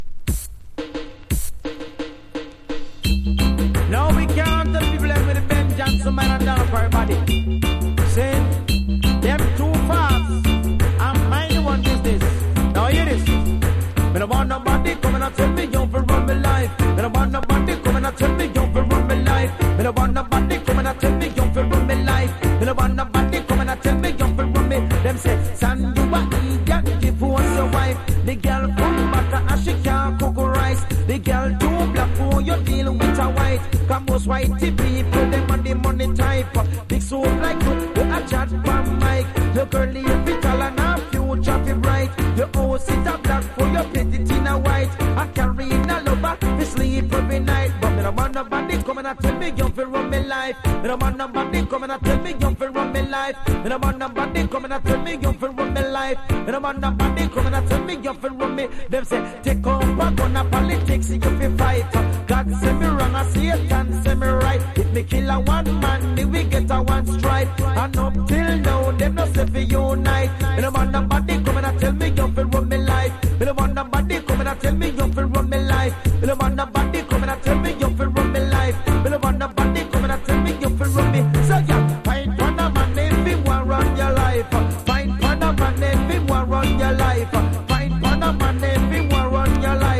• REGGAE-SKA
早口なヴォーカルとアイデアに富んだリリックがGOOD!!
DANCE HALL